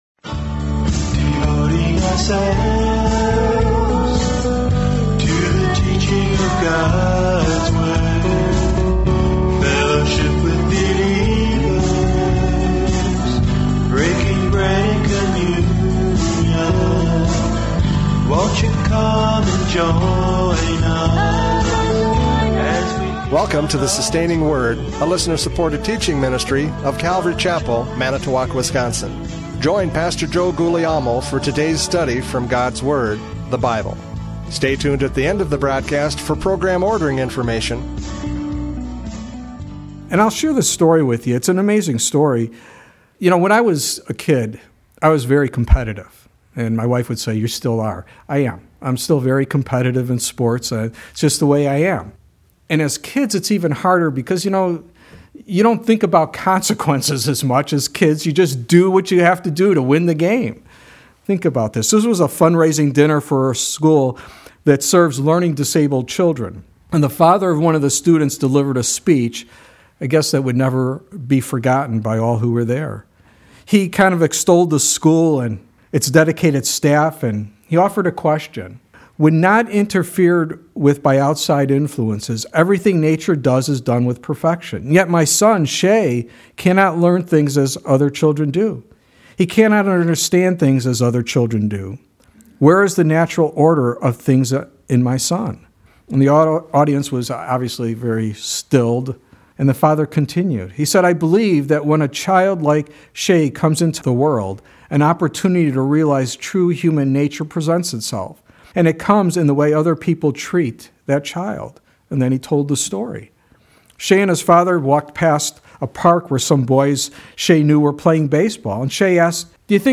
John 13:21-30 Service Type: Radio Programs « John 13:21-30 The Betrayer!